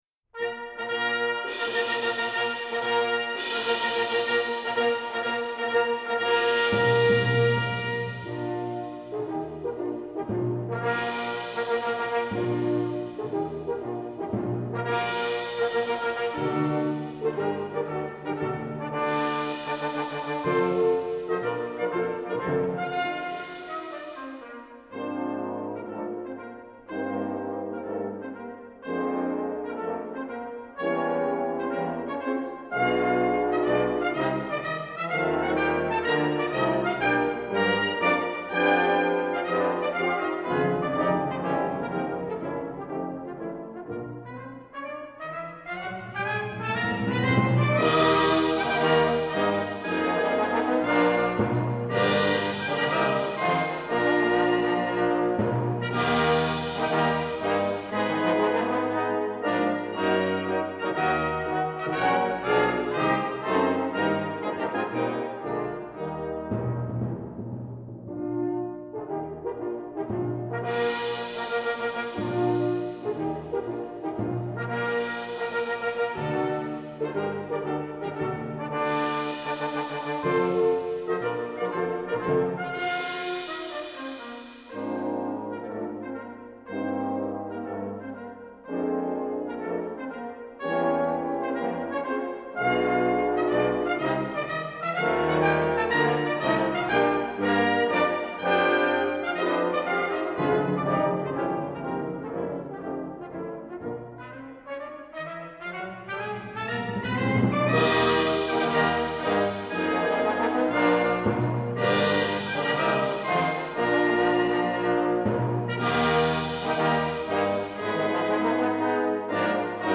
Brass Choir (4.4.3.1.1.perc)